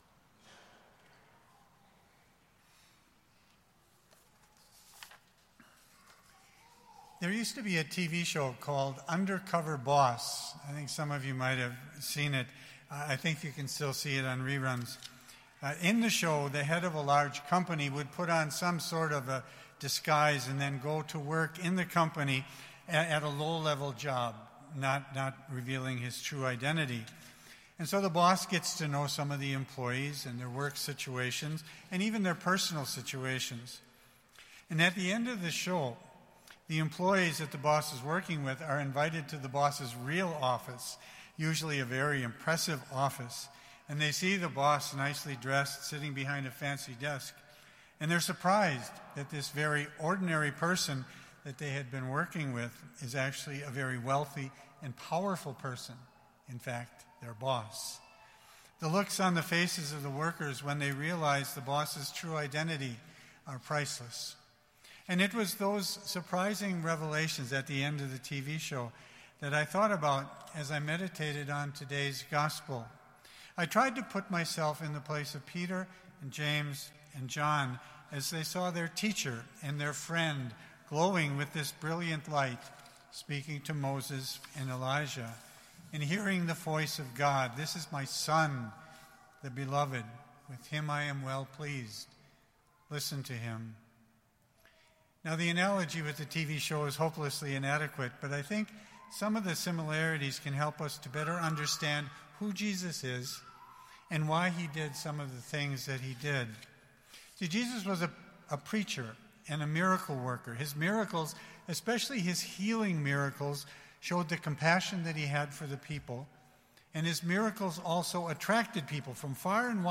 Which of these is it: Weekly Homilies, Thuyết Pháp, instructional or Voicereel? Weekly Homilies